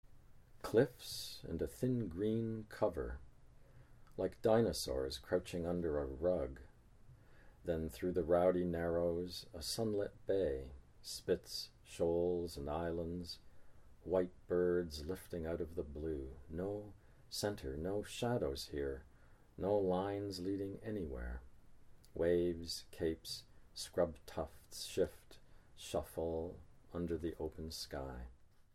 John Steffler reads [cliffs and a thin green] from The Grey Islands